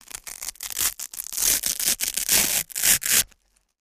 Velcro is pulled apart at various speeds. Tear, Velcro Rip, Velcro